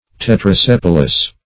Search Result for " tetrasepalous" : The Collaborative International Dictionary of English v.0.48: Tetrasepalous \Tet`ra*sep"al*ous\, a. [Tetra- + sepal.]
tetrasepalous.mp3